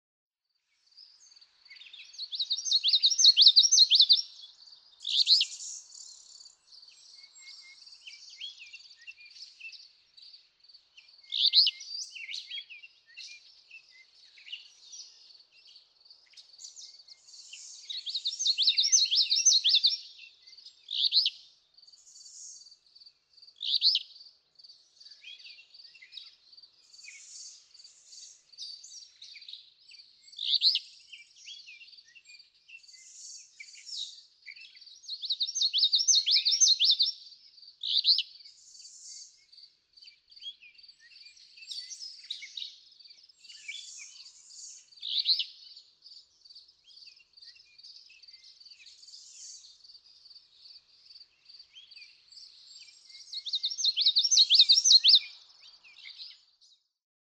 May 28 and 30, 2015. East Leverett Meadow, Leverett, Massachusetts.
♫216, ♫217—longer recordings from those two neighbors
216_Common_Yellowthroat.mp3